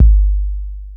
808-Kicks36.wav